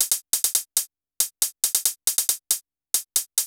CL HH     -R.wav